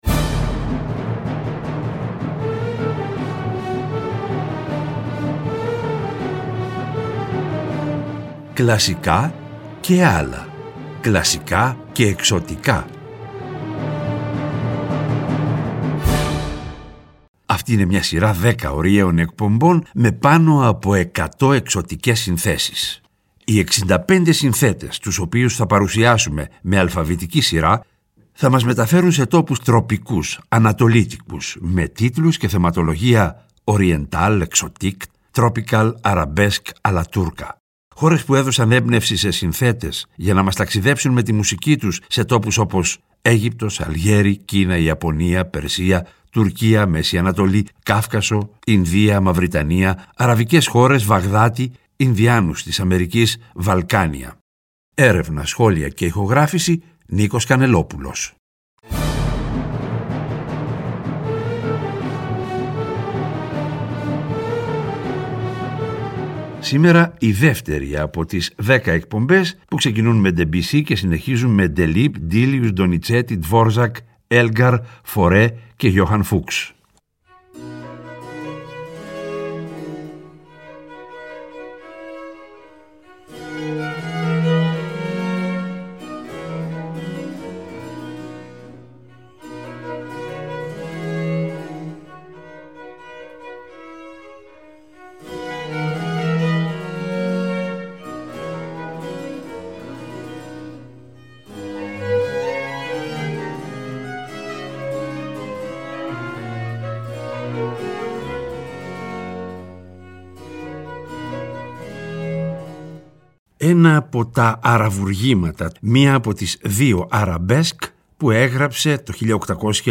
Τον Απρίλιο τα «Κλασικά και ..Άλλα» παίρνουν χρώμα Ανατολής και γίνονται «Κλασικά και …Εξωτικά», σε μια σειρά 10 ωριαίων εκπομπών, με πάνω από 100 εξωτικές συνθέσεις.
Και, προς το τέλος κάθε εκπομπής, θα ακούγονται τα… «άλλα» μουσικά είδη, όπω ς μιούζικαλ, μουσική του κινηματογράφου -κατά προτίμηση σε συμφωνική μορφή- διασκευές και συγκριτικά ακούσματα.